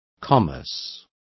Complete with pronunciation of the translation of commerce.